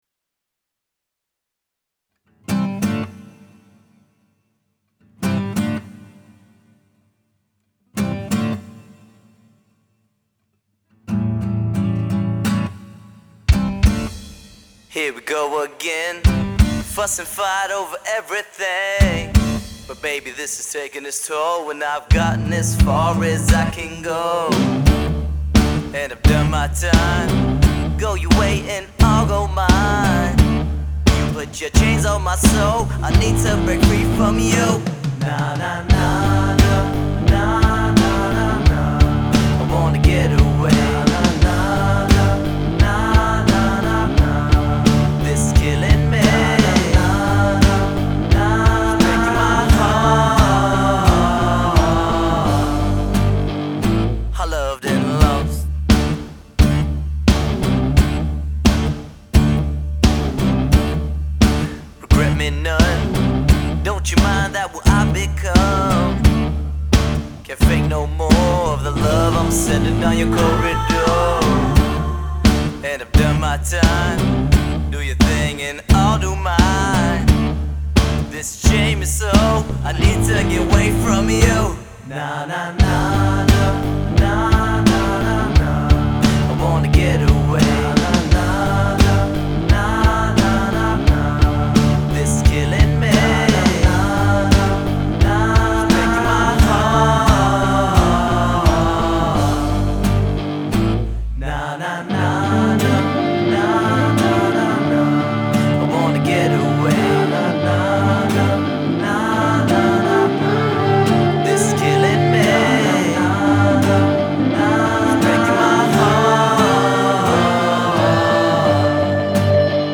home recorded songs